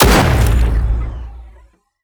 AntiMaterialRifle_1p_02.wav